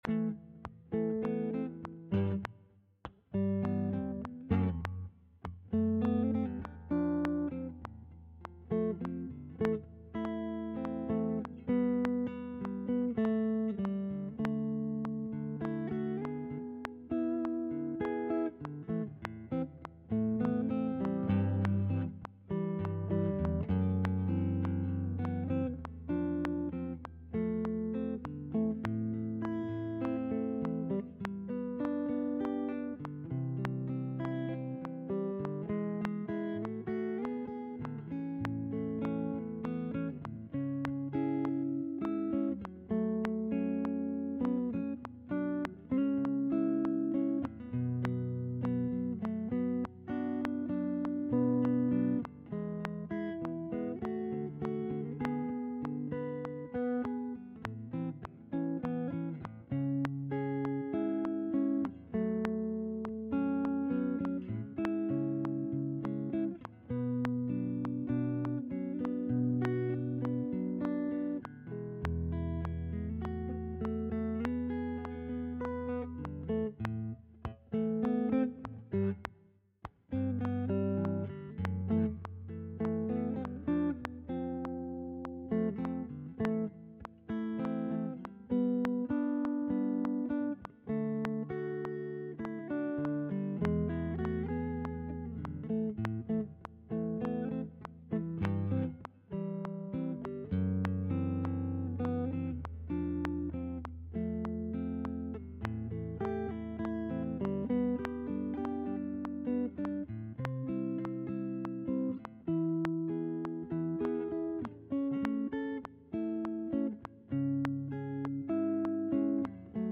in Am at 100 BPM.